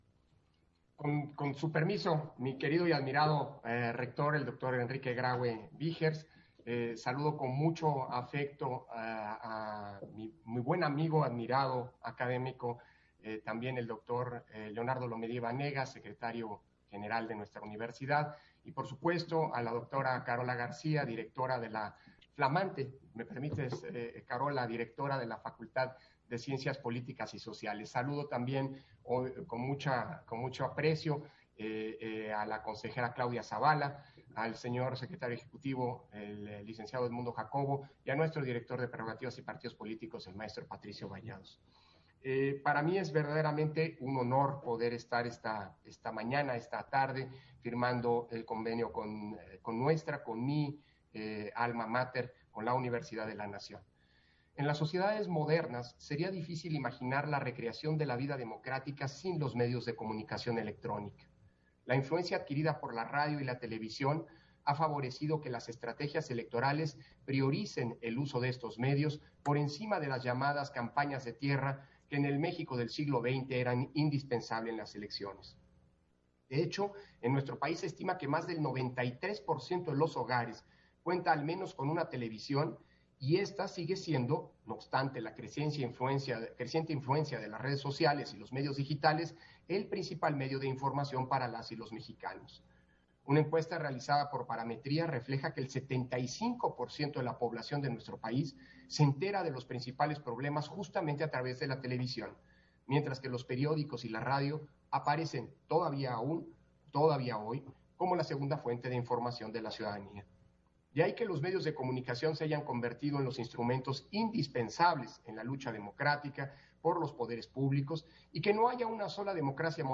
Intervención de Lorenzo Córdova, en la firma de Convenio de Colaboración en materia de monitoreo INE-UNAM